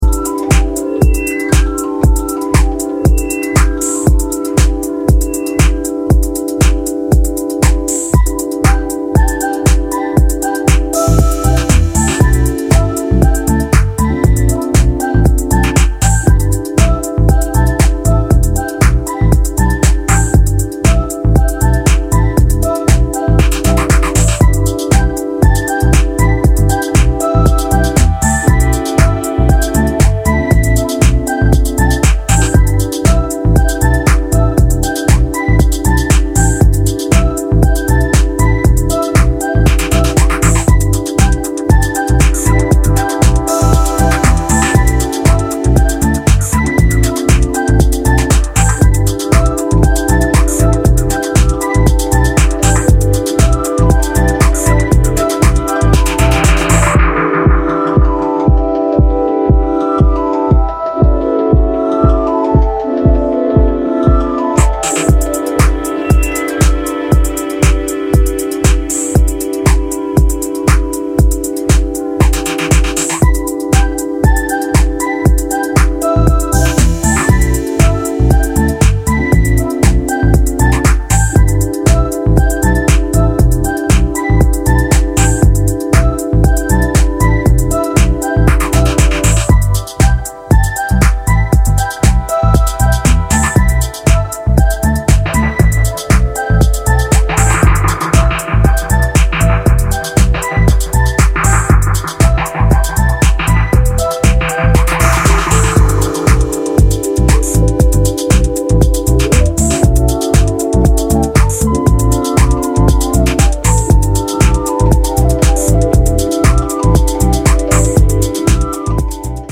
cálidos temas de deep house